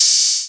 DDW3 OPN HAT 4.wav